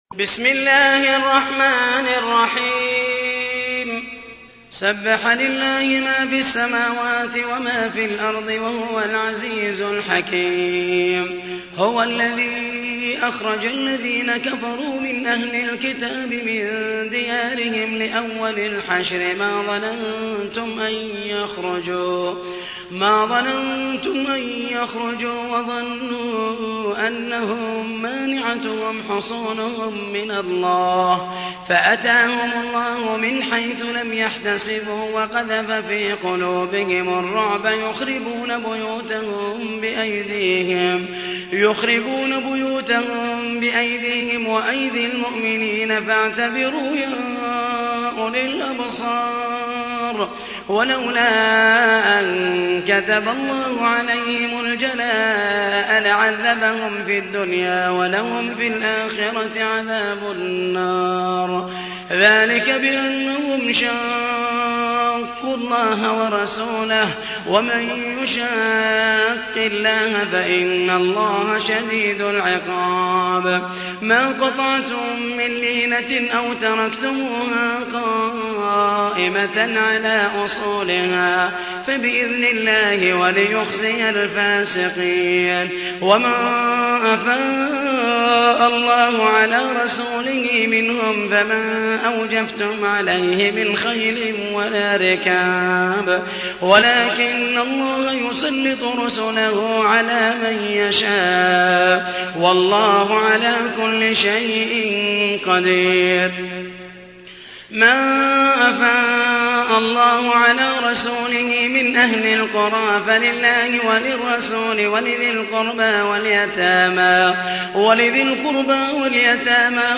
تحميل : 59. سورة الحشر / القارئ محمد المحيسني / القرآن الكريم / موقع يا حسين